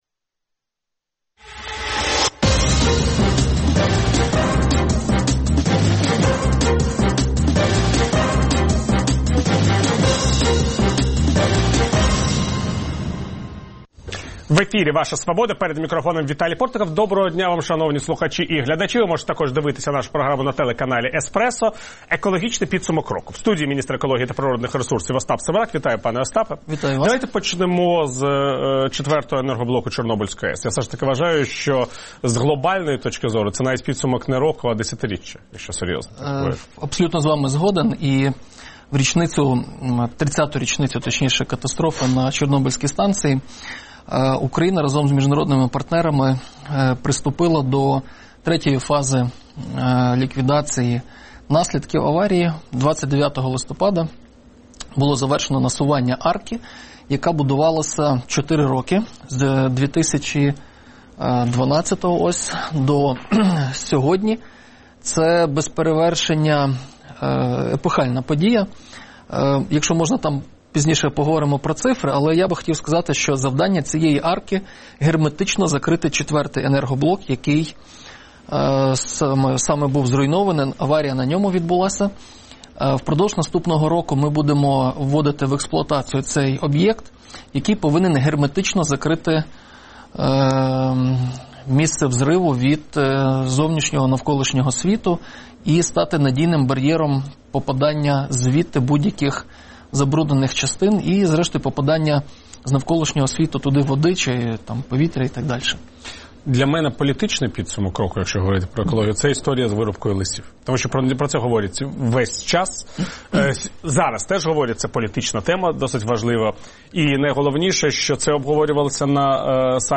Ваша Свобода | Інтерв’ю з міністром екології та природних ресурсів Остапом Семераком